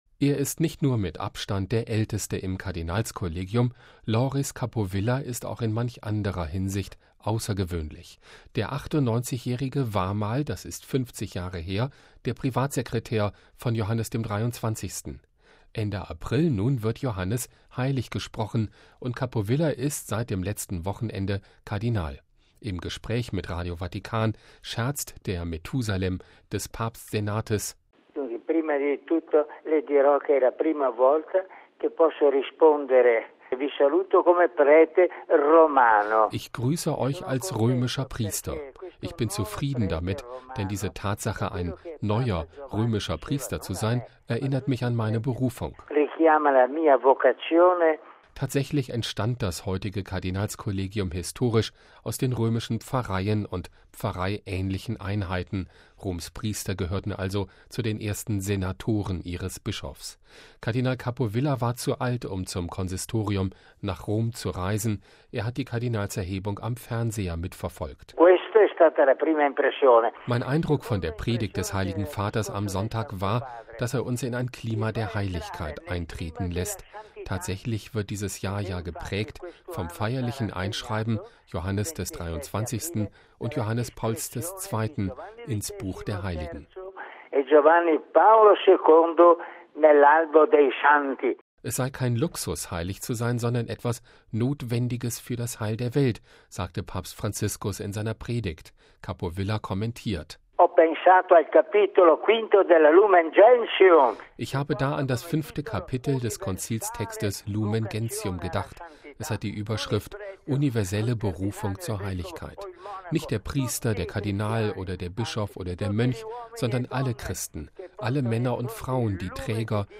Im Gespräch mit Radio Vatikan scherzt der Methusalem des Papst-Senates: